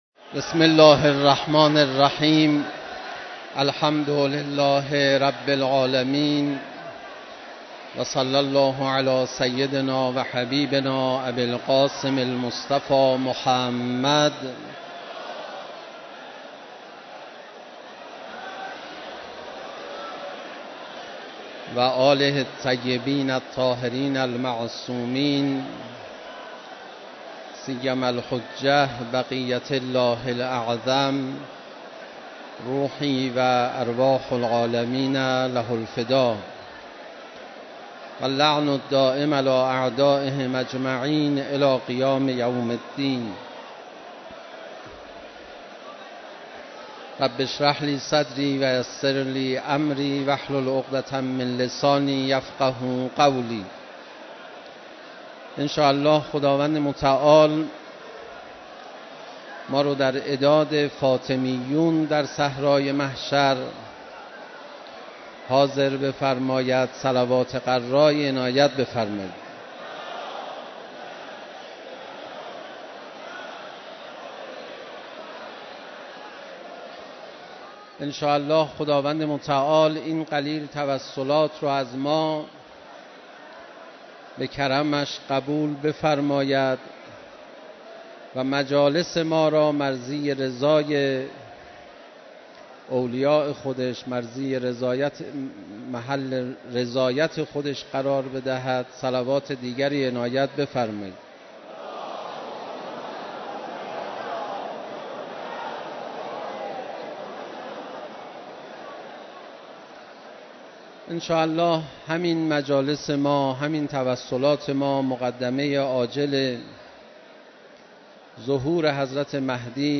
دریافت 0 bytes گروه فیلم و صوت مشرق- د ومین شب از مراسم عزاداری حضرت فاطمه‌ی زهرا سلام‌الله‌علیها ، با حضور حضرت آیت‌الله خامنه‌ای رهبر انقلاب اسلامی، مردم دلداده‌ی اهل بیت عصمت و طهارت علیهم‌السلام و جمعی از مسئولان کشوری و لشکری در حسینیه‌ی امام خمینی رحمه‌الله برگزار شد.
صوت کامل سخنرانی